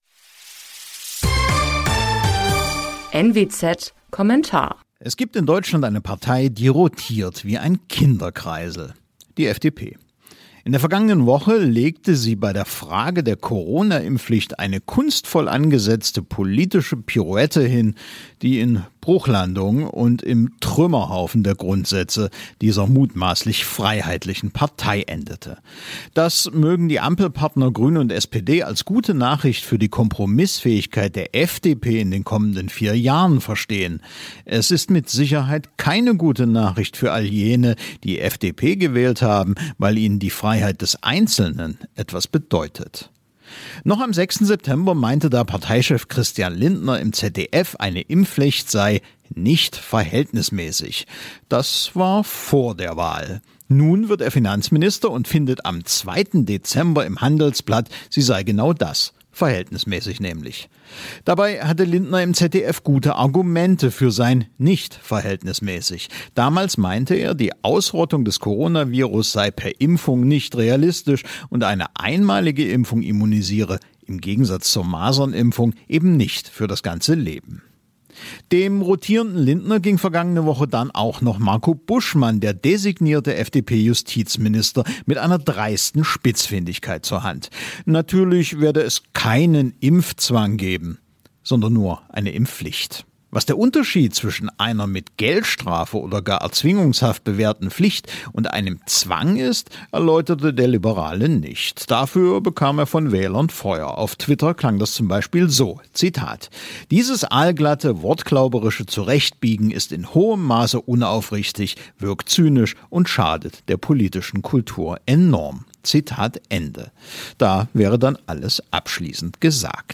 Es ging dieses mal schneller als bei der letzten Regierungsbeteiligung: Die FDP verabschiedet sich in Lichtgeschwindigkeit von politischen Grundsätzen. Eine Audio-Kolumne